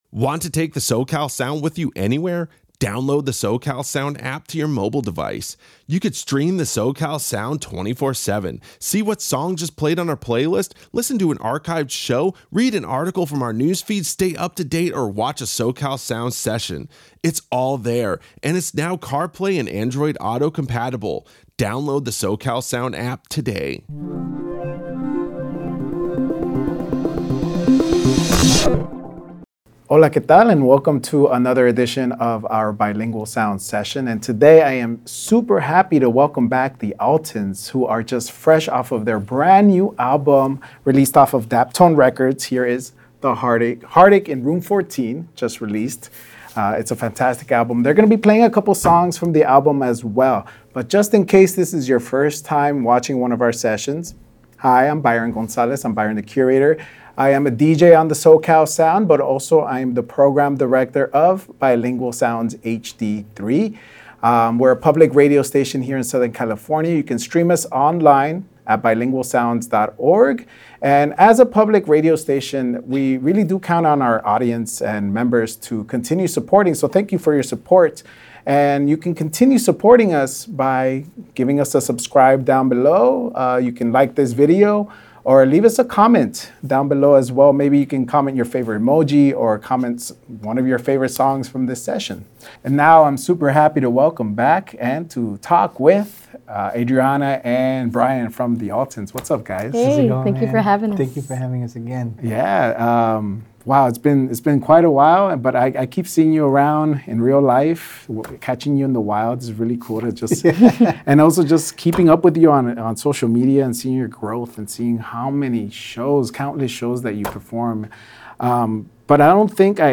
Los Angeles's public alternative rock radio.